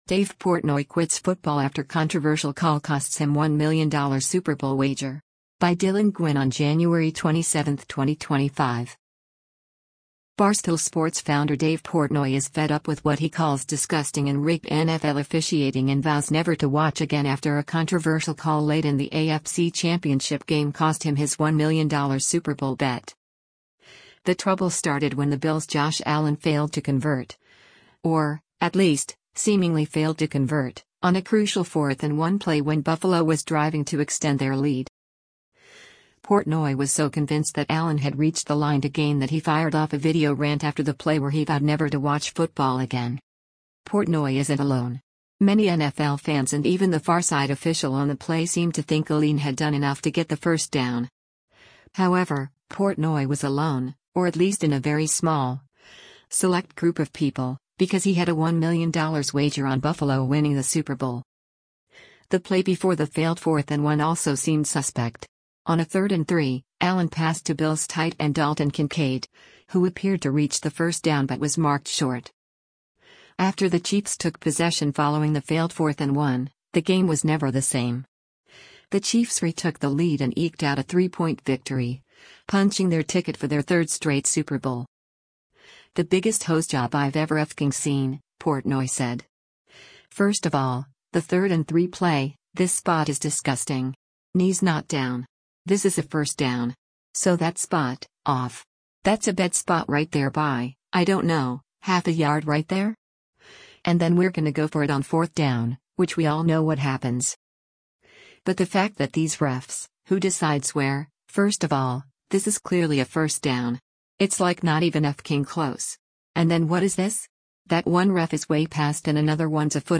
Portnoy was so convinced that Allen had reached the line-to-gain that he fired off a video rant after the play where he vowed never to watch football again.